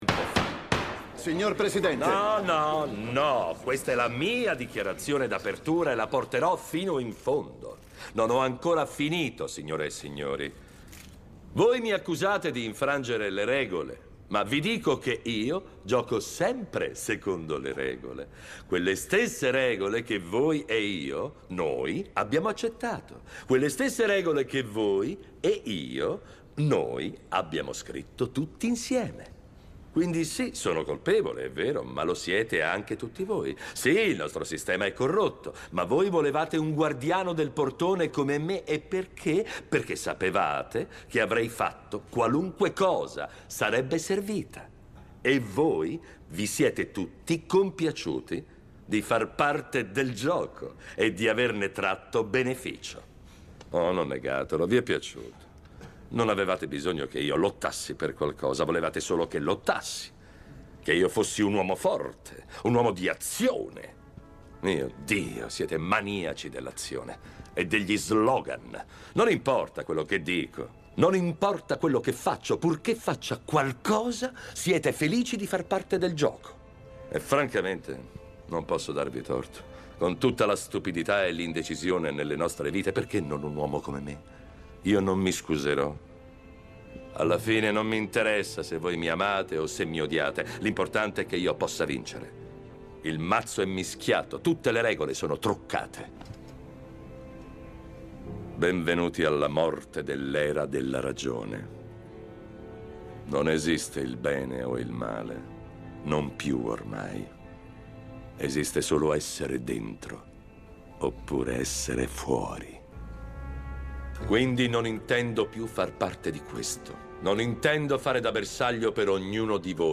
voce di Roberto Pedicini nel telefilm "House of Cards - Gli intrighi del potere", in cui doppia Kevin Spacey.